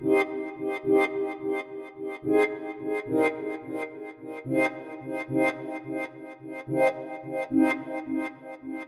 标签： 108 bpm House Loops Synth Loops 1.50 MB wav Key : Unknown
声道立体声